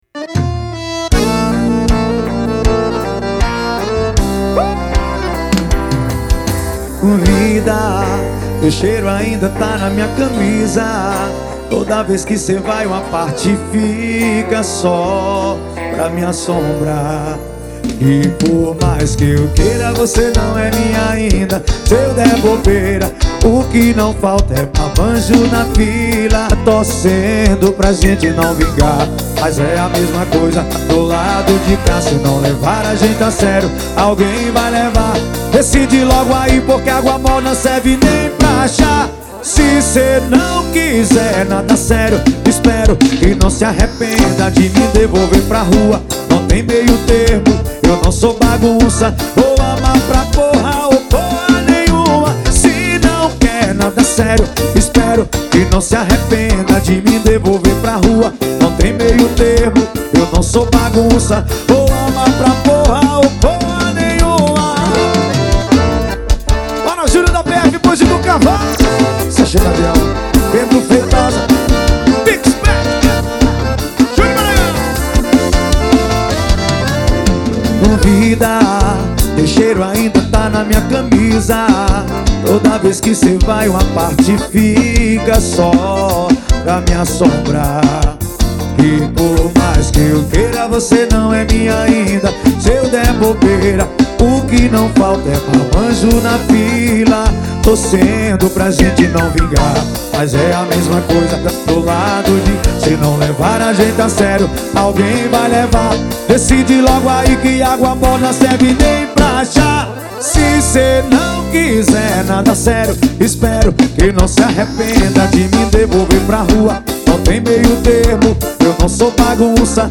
2024-02-14 18:42:51 Gênero: FORRO Views